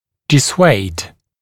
[dɪ’sweɪd][ди’суэйд]отговаривать, разубеждать